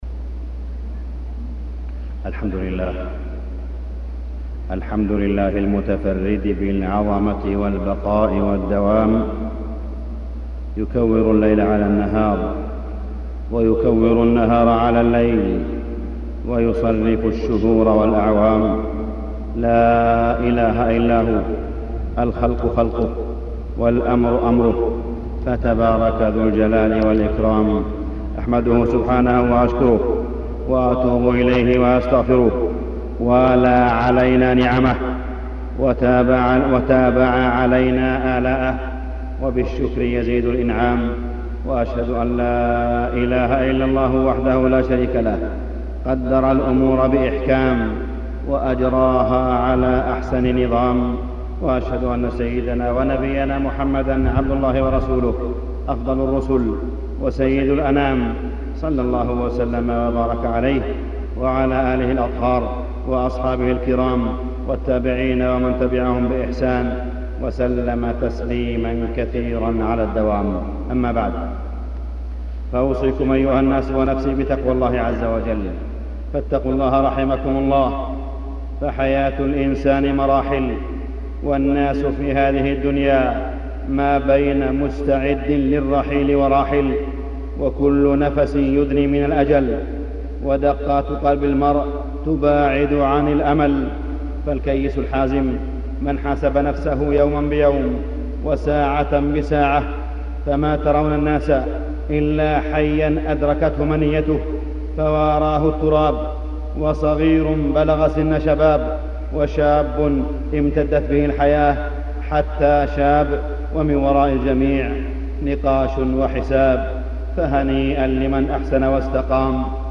تاريخ النشر ٤ محرم ١٤٢٧ هـ المكان: المسجد الحرام الشيخ: معالي الشيخ أ.د. صالح بن عبدالله بن حميد معالي الشيخ أ.د. صالح بن عبدالله بن حميد وقفات مع عام فات